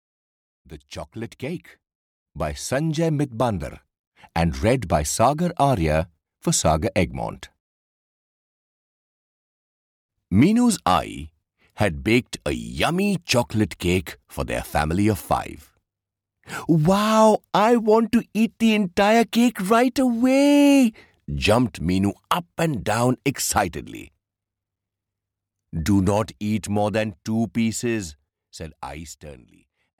The Chocolate Cake (EN) audiokniha
Ukázka z knihy